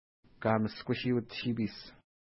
ID: 192 Longitude: -60.7020 Latitude: 55.5251 Pronunciation: ka:məʃkuʃi:ut-ʃi:pi:s Translation: Grassy Place River (small) Feature: river Explanation: Named in reference to lake Kamashkushiut (no 191) from which it flows.